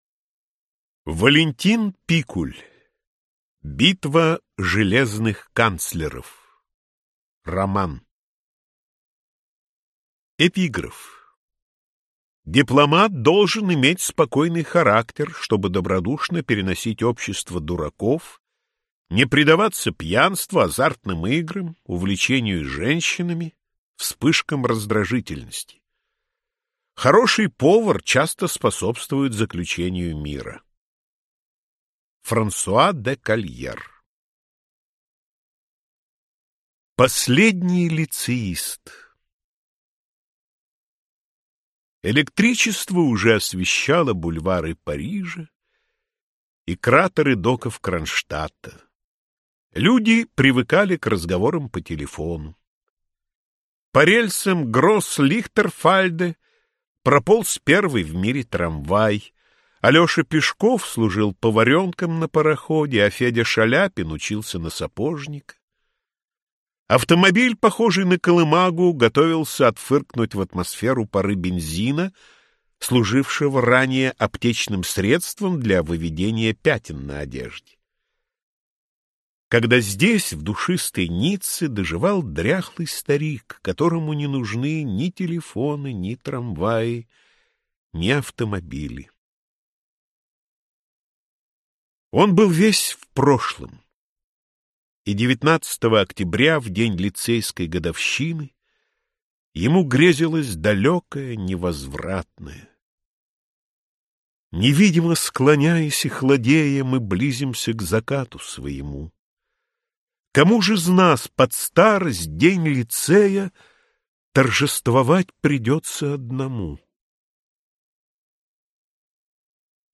Аудиокнига Битва железных канцлеров | Библиотека аудиокниг